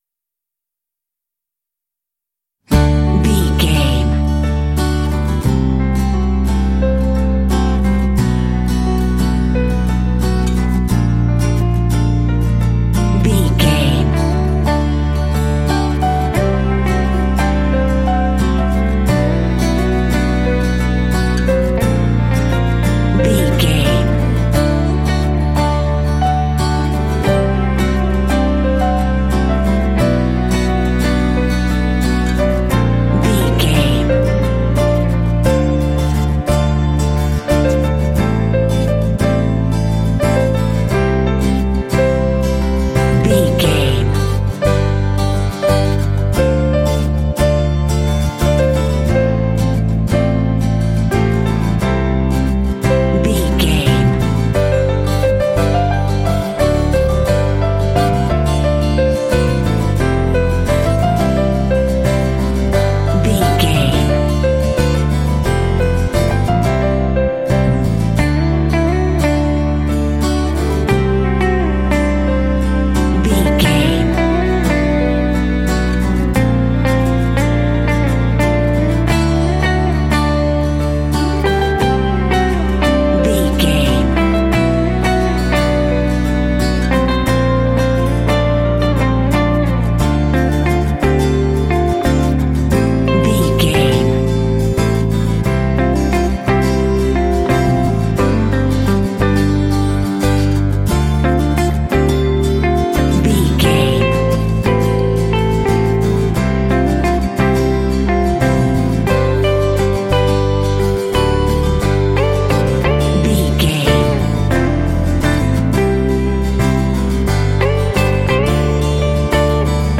Ionian/Major
light
dreamy
sweet
orchestra
horns
strings
percussion
cello
acoustic guitar
cinematic
pop